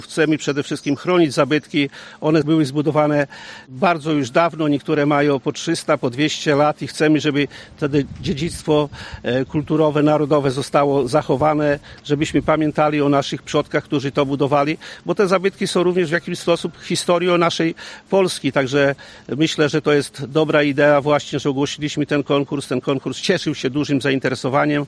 Dziś w Szczepankowie nastąpiło rozstrzygnięcie konkursów w ramach ochrony zabytków w powiecie łomżyńskim.
O rządowym wsparciu mówił podczas konferencji prasowej poseł Prawa i Sprawiedliwości, Kazimierz Gwiazdowski: